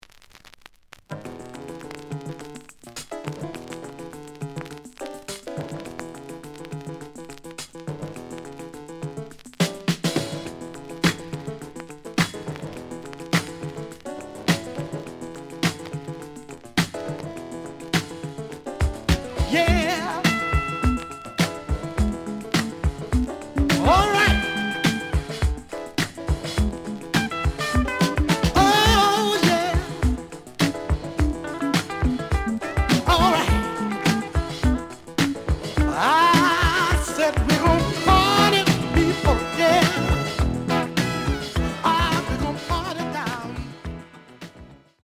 The listen sample is recorded from the actual item.
●Format: 7 inch
●Genre: Soul, 70's Soul